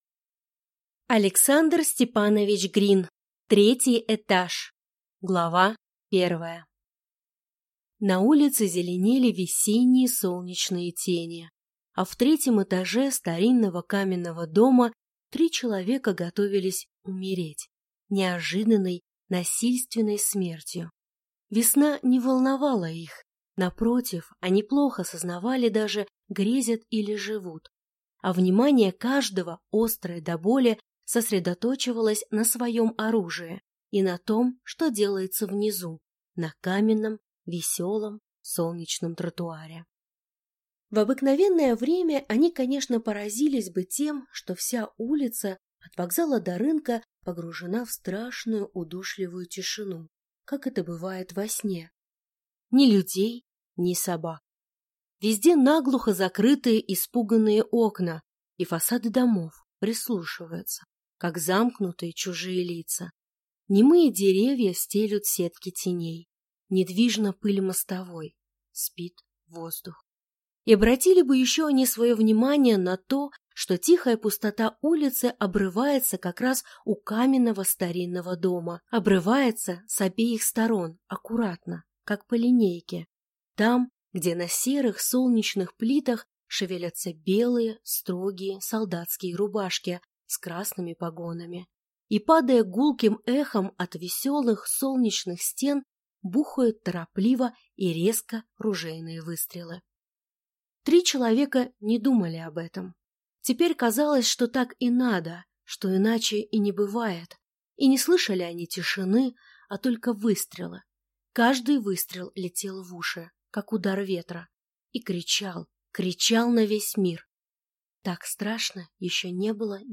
Аудиокнига Третий этаж | Библиотека аудиокниг